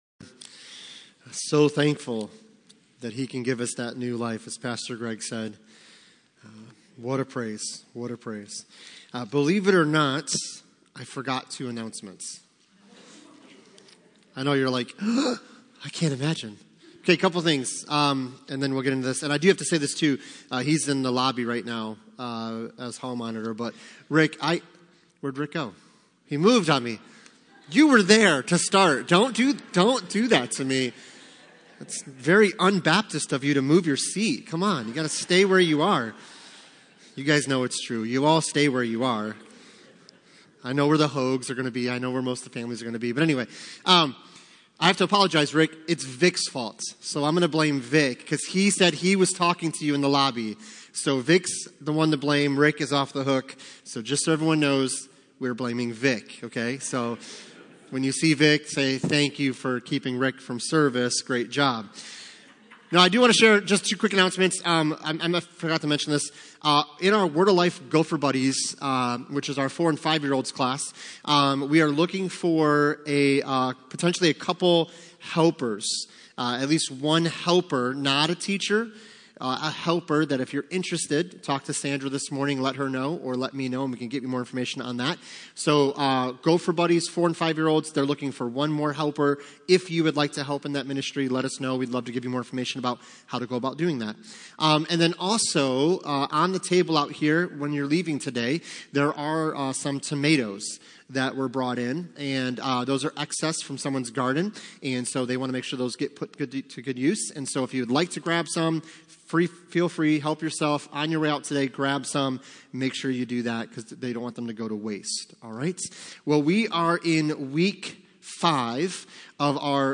Passage: Hebrews 12:2 Service Type: Sunday Morning